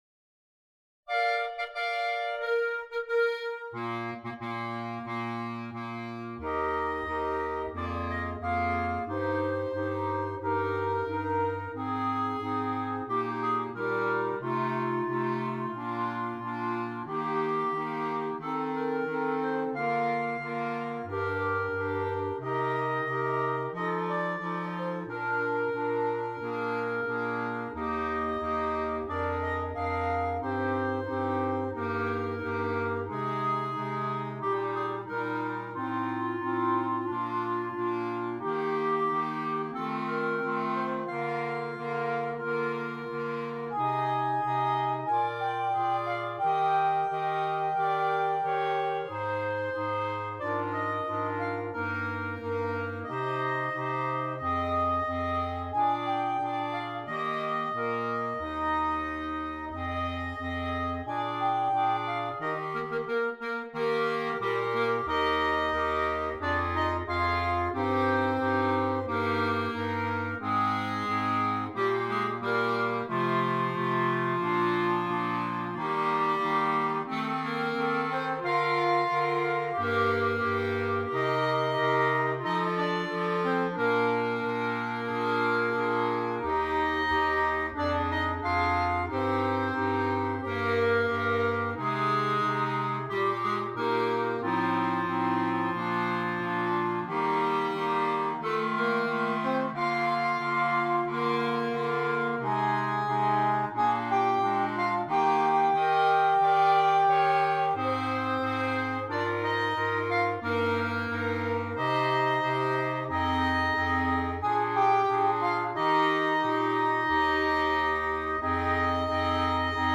Voicing: Bb Clarinet Quintet